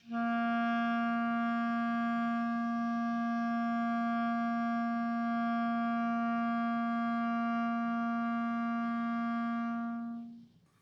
Clarinet
DCClar_susLong_A#2_v2_rr1_sum.wav